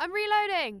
Voice Lines / Combat Dialogue
Maddie Reloading.wav